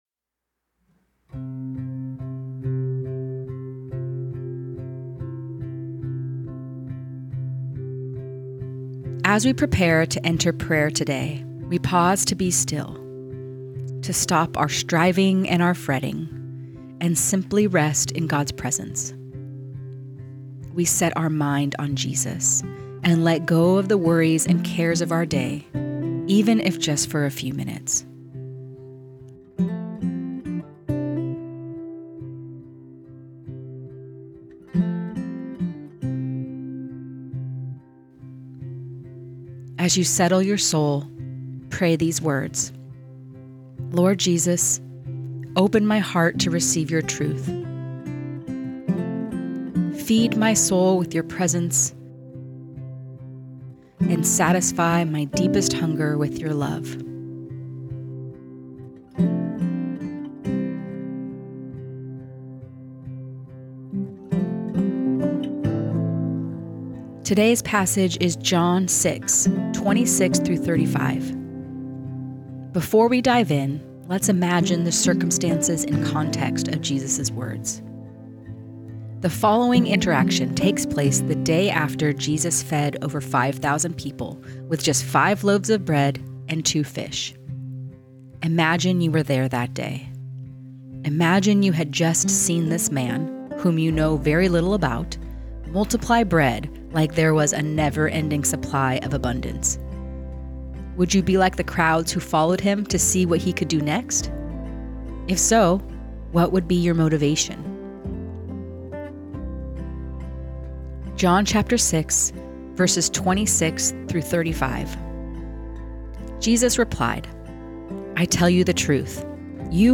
Guided Listening Practice